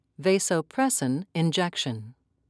(vay-soe-press'in)